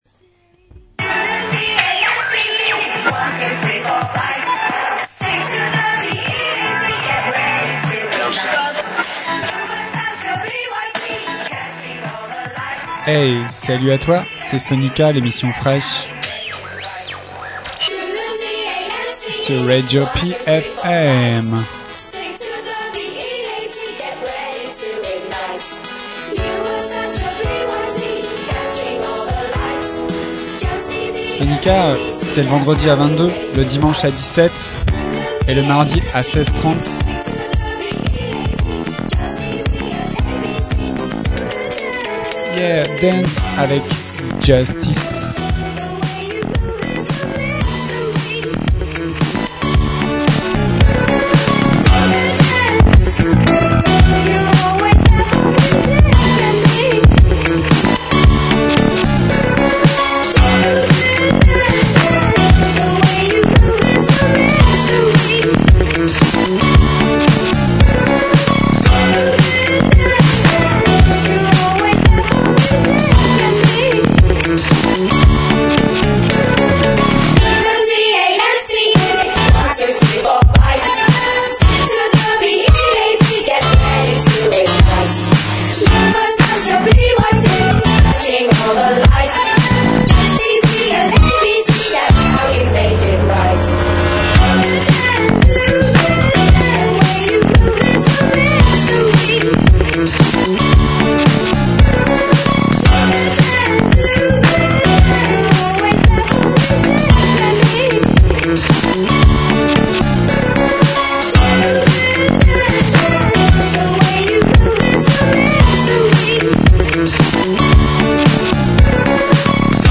SoniKa en live sur RADIO PFM 99.9 le dimanche à 17h !!